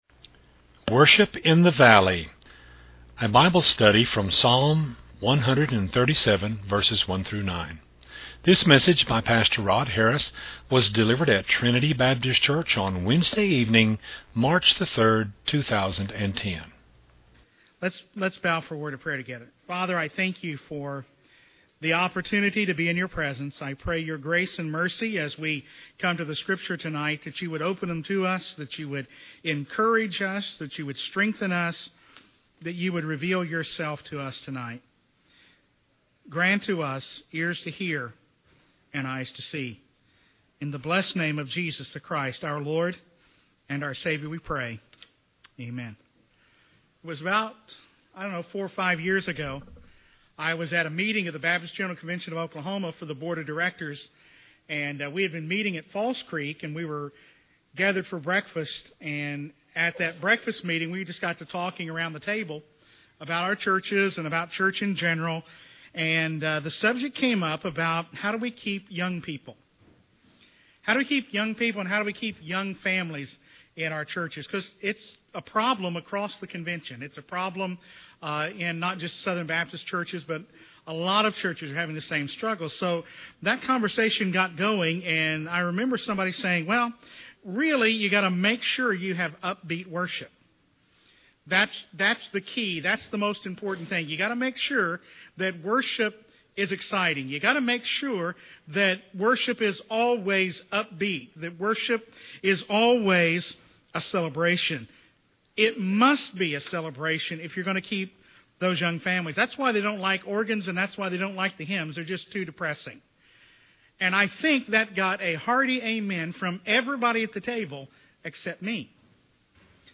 A Bible study from Psalm 137:1-9